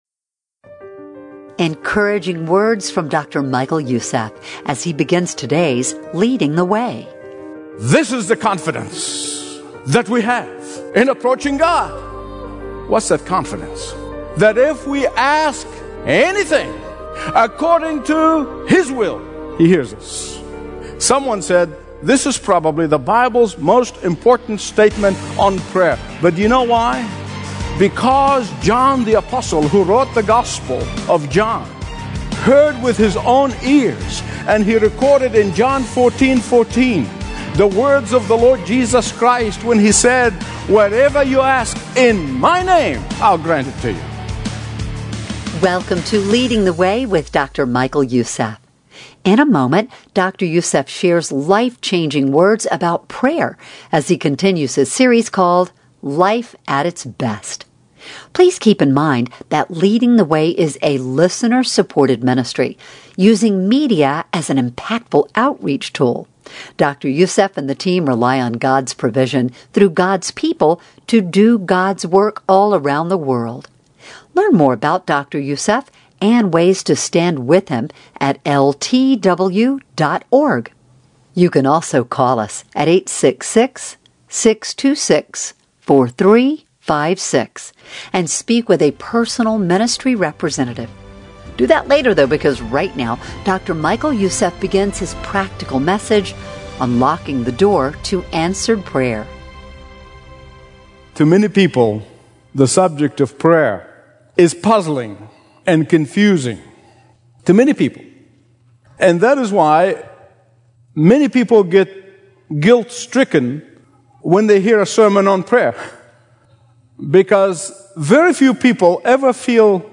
Listen to Dr. Michael Youssef's Daily Teaching onUnlocking The Door To Answered Prayer in HD Audio of Dr. Youssef's Greatest Sermons.